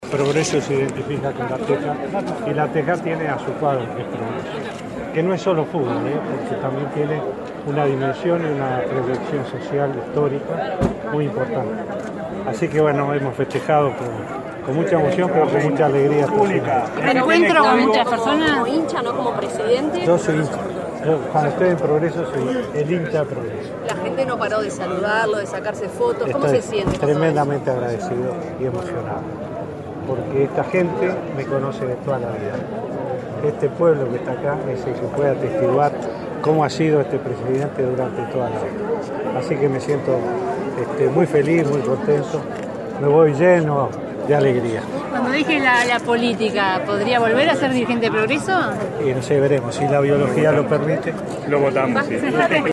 El presidente de la República, Tabaré Vázquez, participó en la celebración del centenario del Club A. Progreso, emblemático centro deportivo y social del barrio La Teja, de Montevideo, dedicado al fútbol, el cual encabezó entre 1979 y 1989. Durante los festejos subrayó la identificación mutua entre el barrio y el club, además de recordar anécdotas de su juventud, que comparte con vecinos, socios y adherentes a la institución.